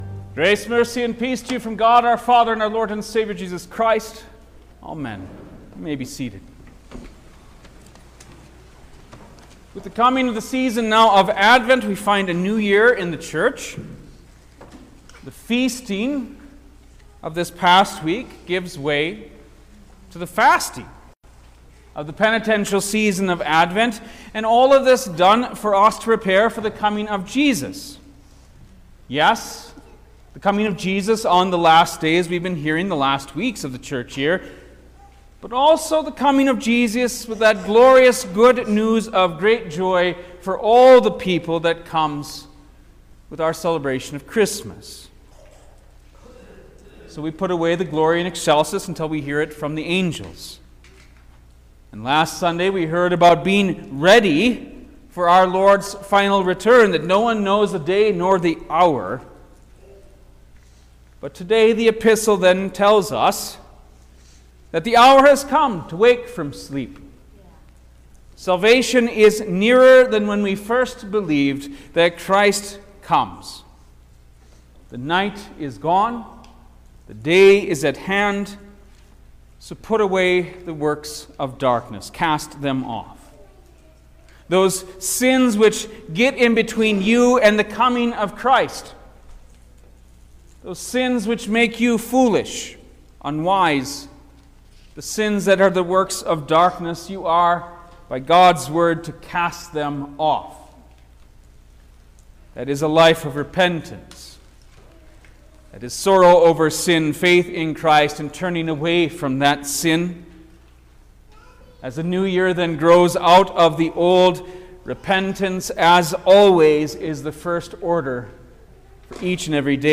December-1_2024_First-Sunday-in-Advent_Sermon-Stereo.mp3